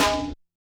percussion01.wav